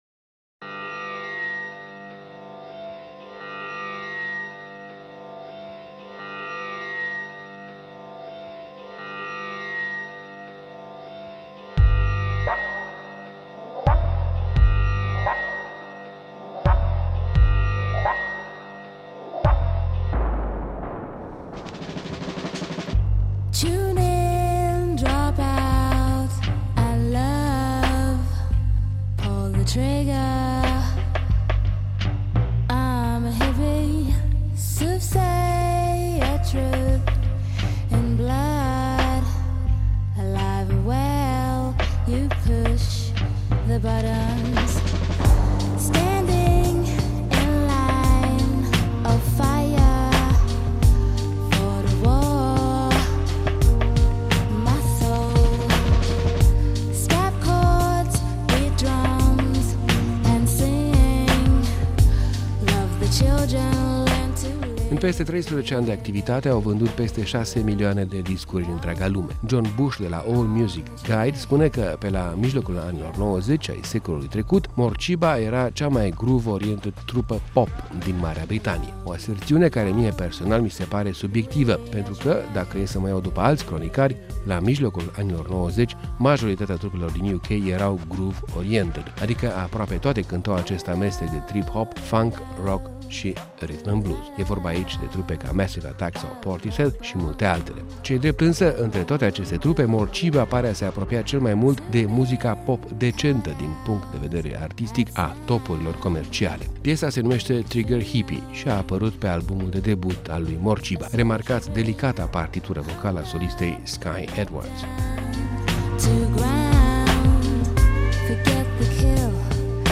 Cea mai groove oriented trupă pop din Marea Britanie.